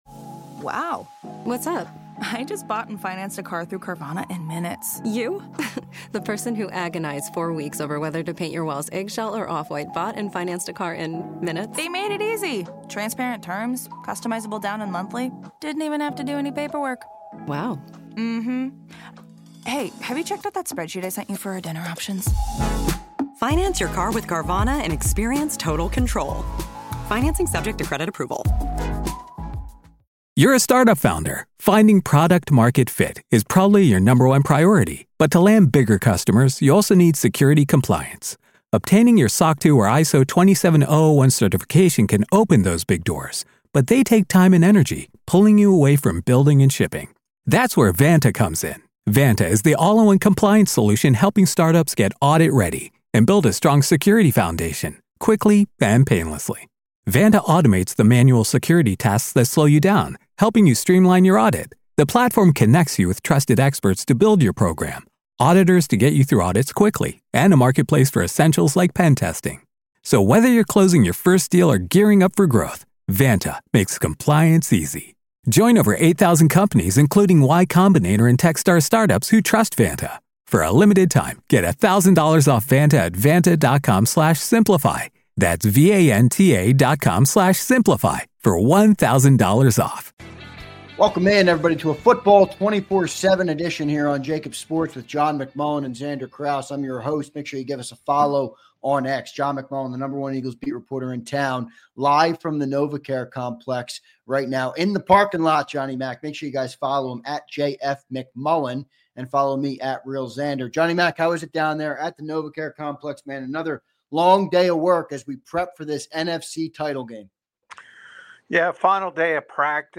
Joins Live from Novacare Complex